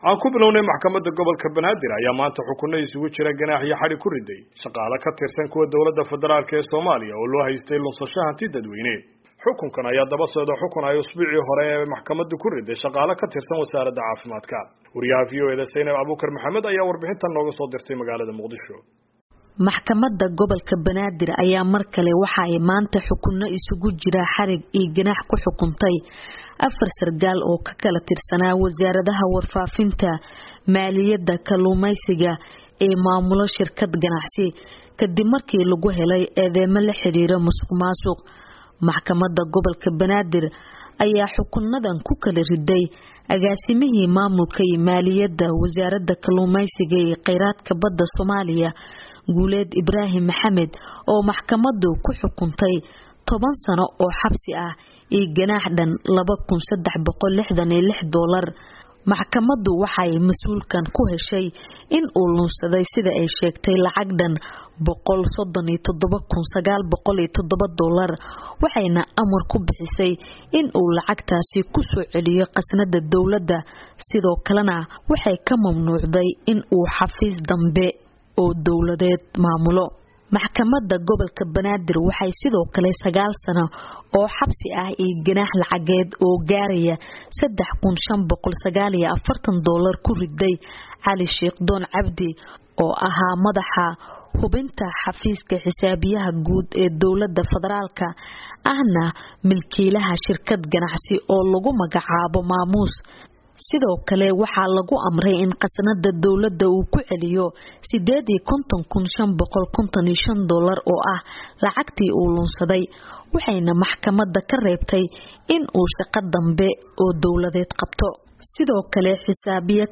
warbixintan Muqdisho ka soo dirtay